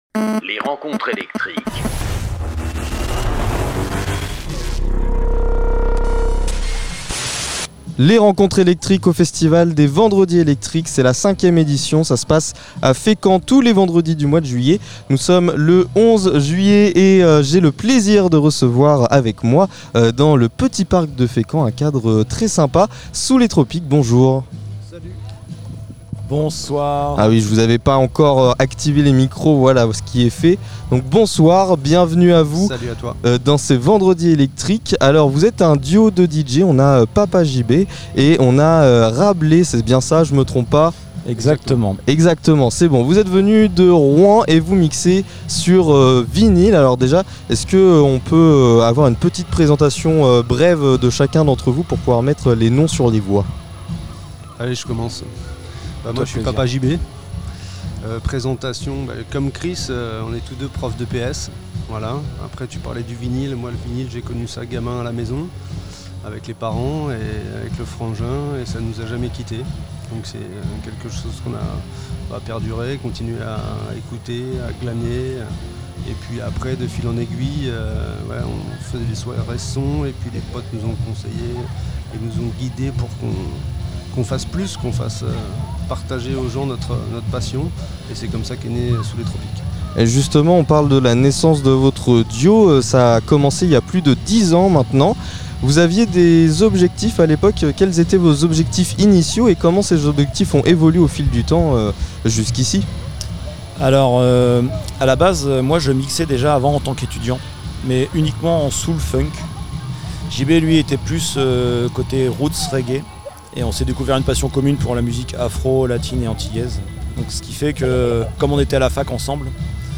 Les rencontres électriques sont les interviews des artistes régionaux qui se produisent lors du festival "Les vendredi électriques" organisés par l'association Art en Sort.